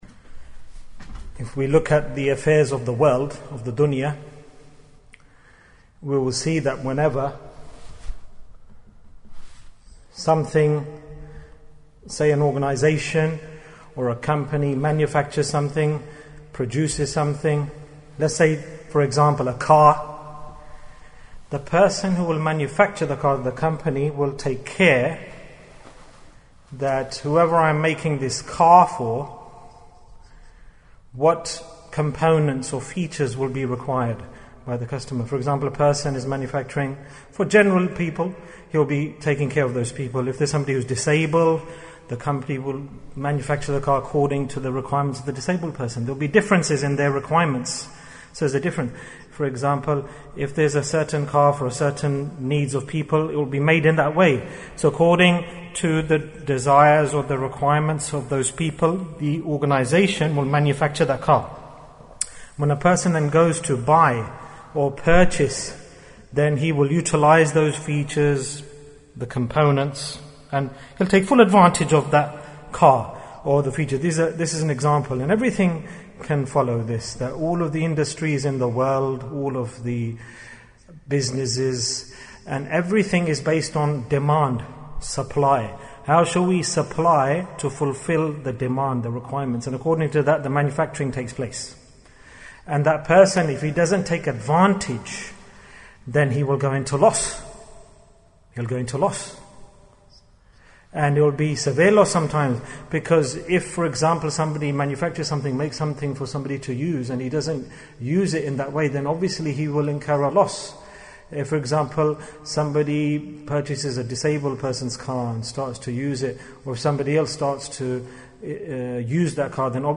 Loss For Not Doing Dhikr Bayan, 41 minutes24th January, 2020